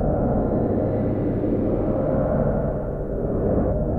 Index of /musicradar/sparse-soundscape-samples/Sample n Hold Verb Loops